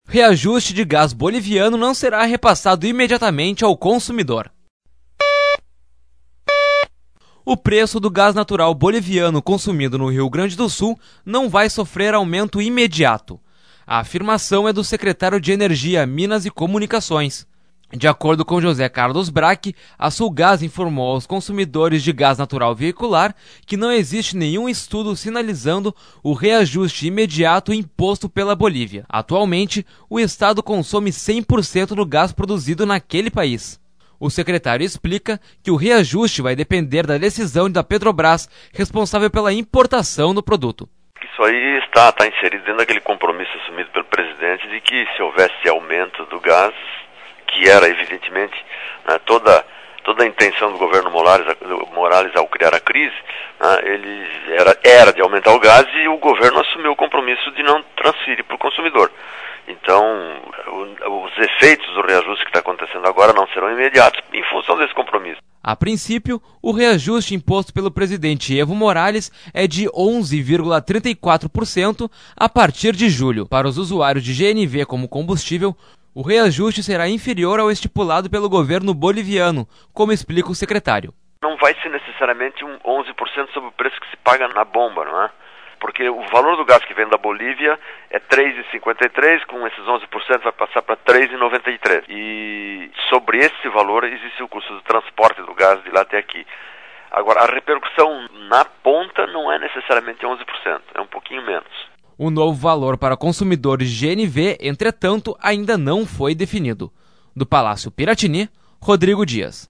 O preço do gás natural boliviano consumido no Rio Grande do Sul não vai sofrer aumento imediato. A afirmação é do secretário de Energia, Minas e Comunicações, José Carlos Brack. Sonora: Secretário de Energia, Minas e Comunicações, José Carlos BrackLoca